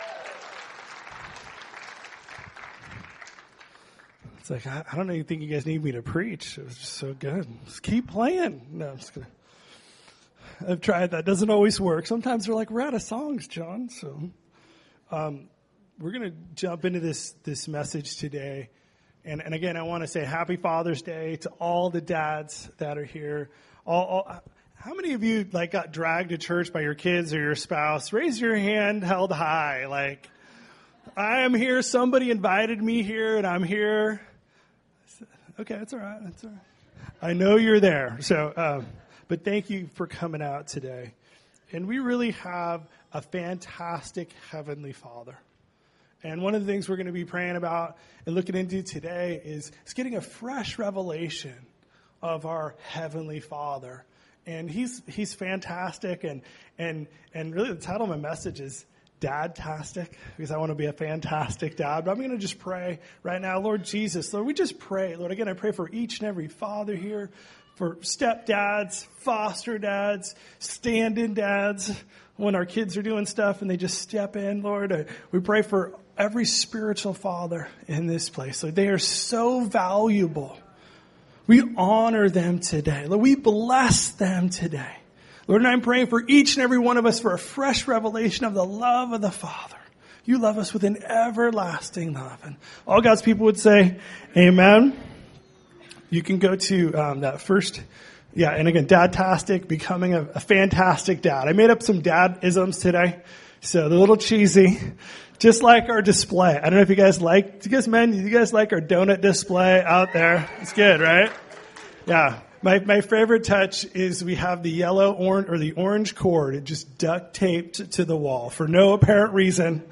Recorded at New Life Christian Center, Sunday, June 17, 2018 at 11 AM.